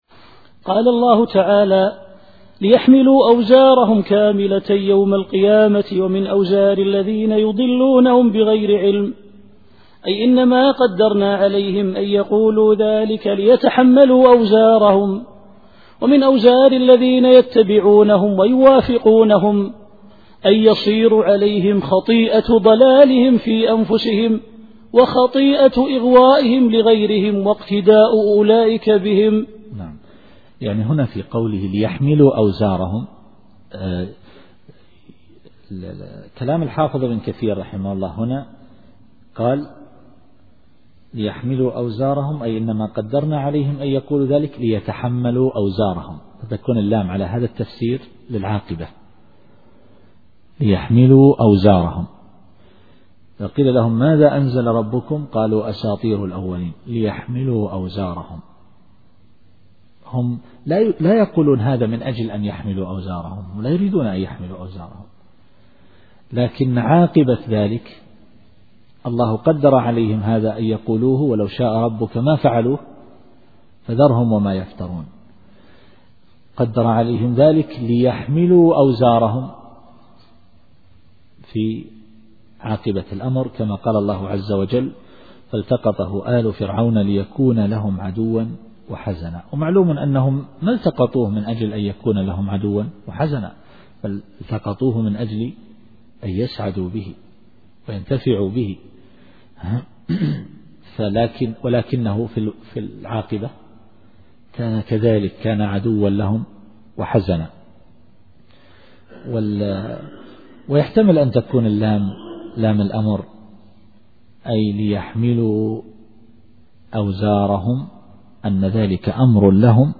التفسير الصوتي [النحل / 25]